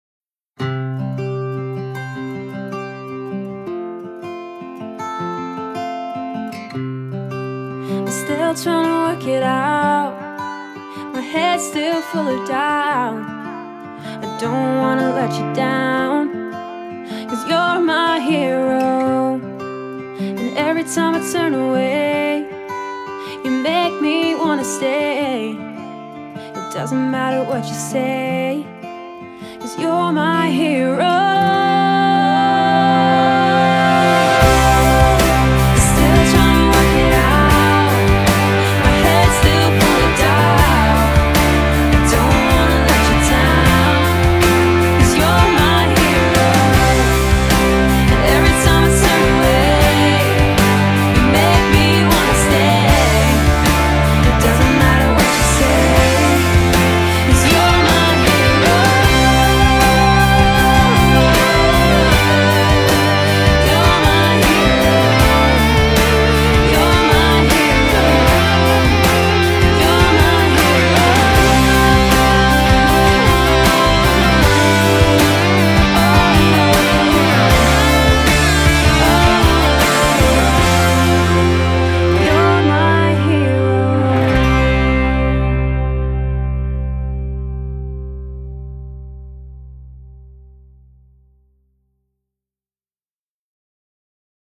ポップで明るいムード
女性 ﾐﾄﾞﾙ